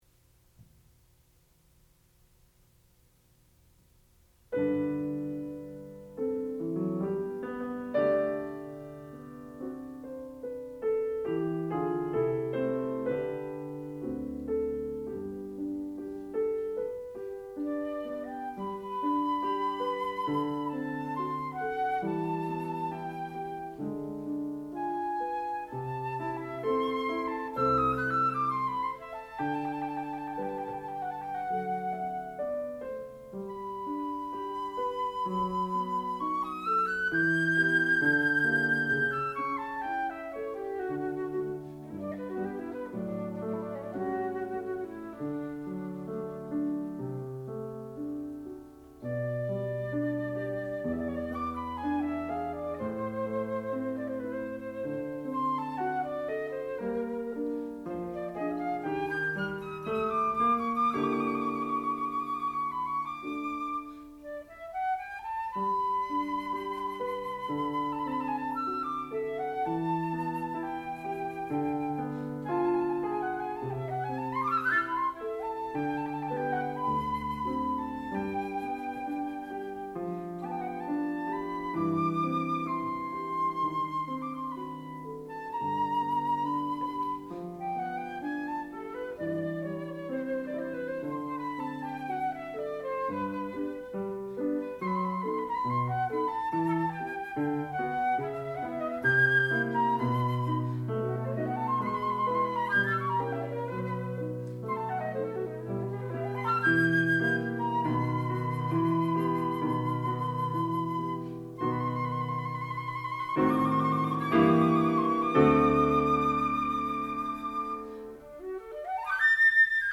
sound recording-musical
classical music
Master's Recital
flute